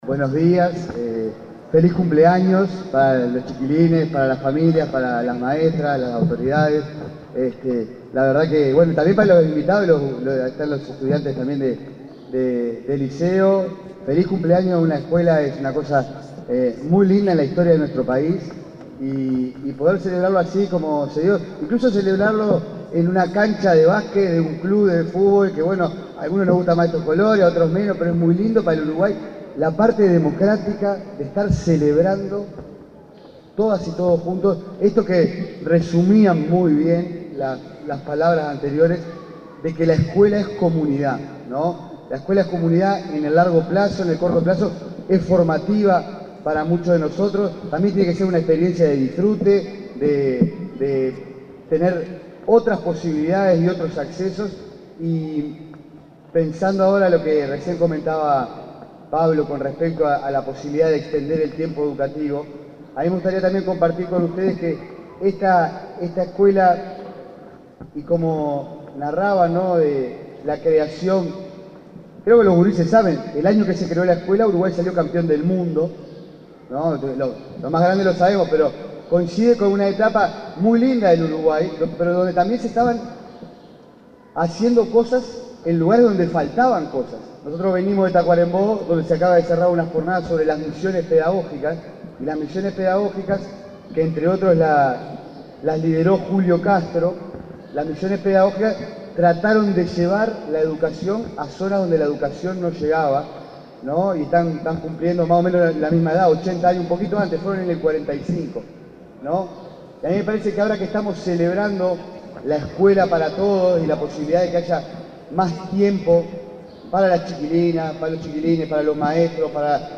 Palabras del director nacional de Educación, Gabriel Quirici
El director nacional de Educación, Gabriel Quirici, se expresó en oportunidad del 75.° aniversario de la escuela n.° 113 de Nueva Palmira, Colonia.